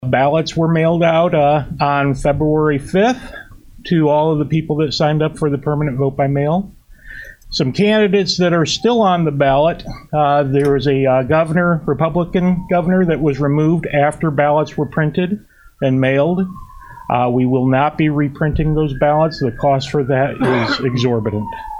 During Wednsday morning’s meeting of the Kankakee County Board’s Executive Committee, Kankakee County Clerk Dan Hendrickson noted mail-in ballots for the upcoming primary election have been mailed out.